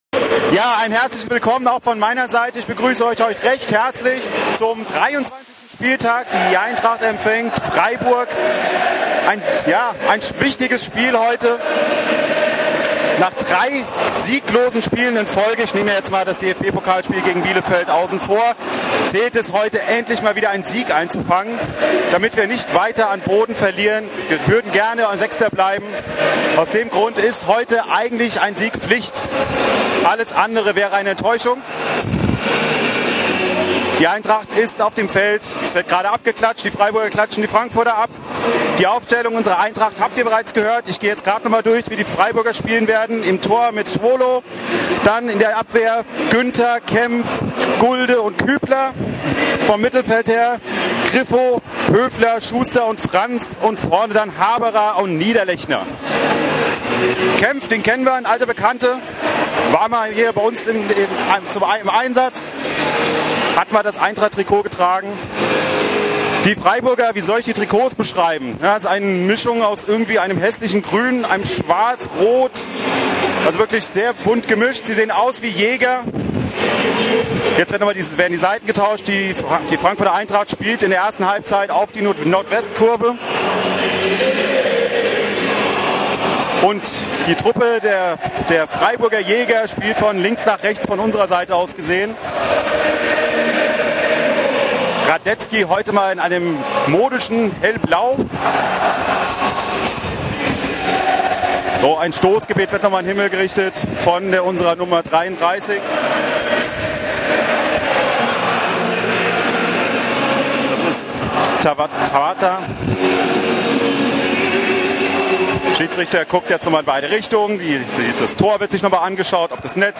Die Spiel-Reportage im Player
Ort Commerzbank-Arena, Frankfurt